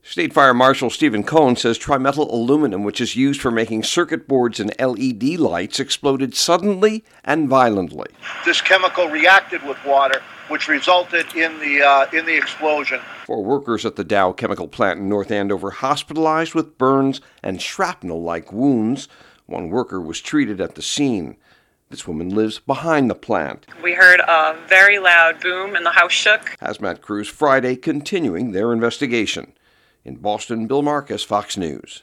(BOSTON) JAN 8 – A CHEMICAL EXPLOSION THURSDAY AFTERNOON AT A DOW CHEMICAL PLANT NORTH OF BOSTON INJURING FIVE WORKERS, THREE OF THEM CRITICALLY. FOX NEWS RADIO’S